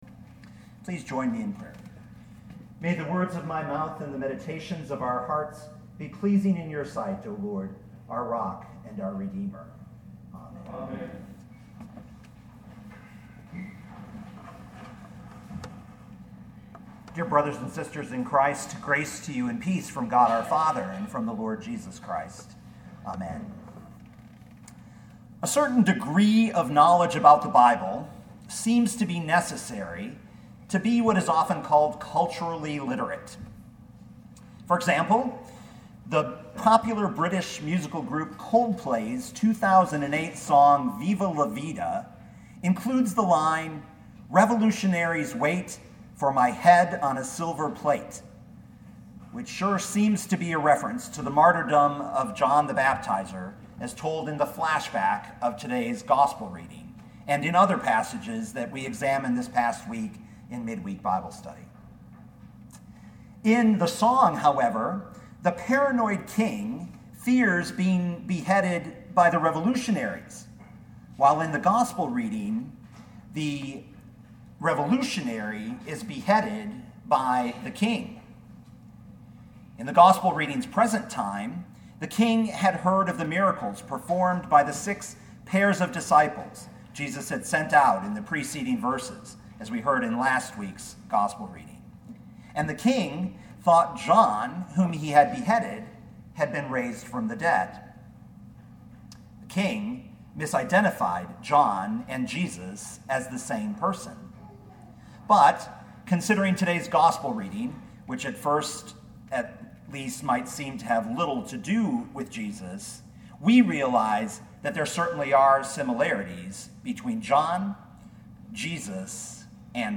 2018 Mark 6:14-29 Listen to the sermon with the player below, or, download the audio.